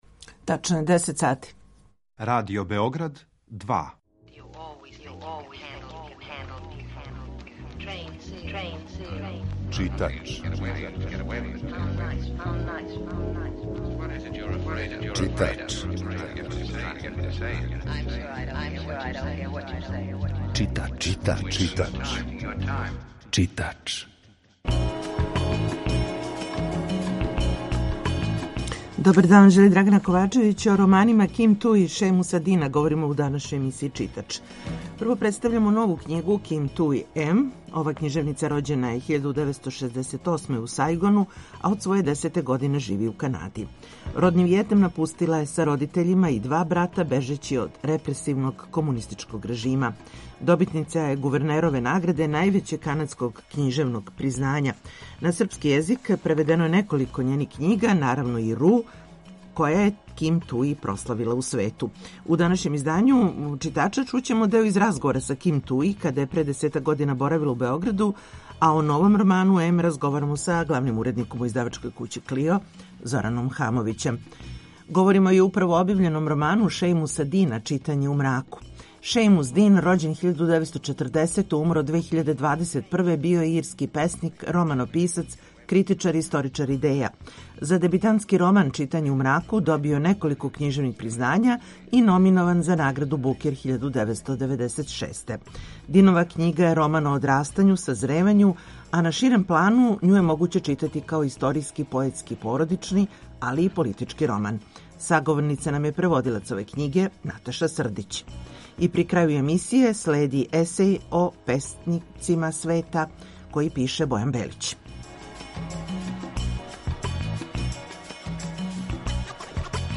Емисија је колажног типа, али је њена основна концепција – прича о светској књижевности